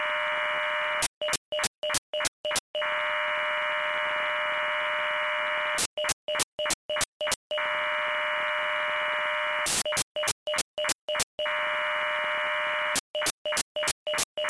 Link-11_UHF.mp3